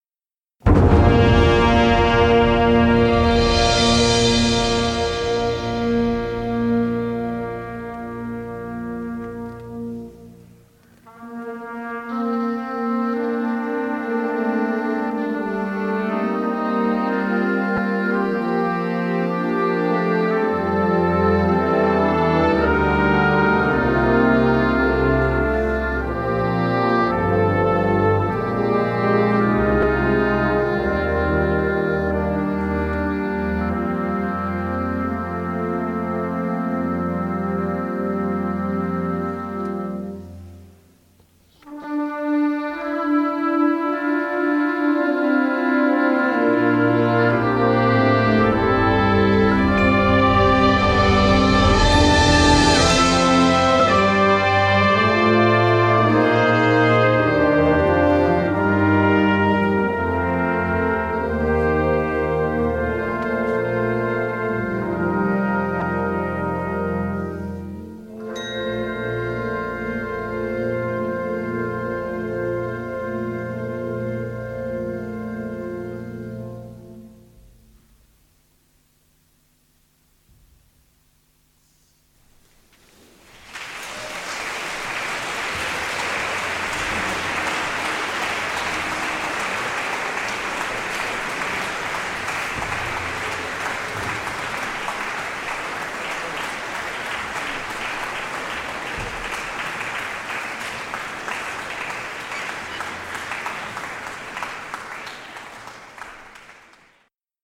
Catégorie Harmonie/Fanfare/Brass-band
Sous-catégorie Orchestre de jeunes (orchestre à vent)